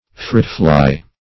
Search Result for " fritfly" : The Collaborative International Dictionary of English v.0.48: Fritfly \Frit"fly`\, n. (Zool.)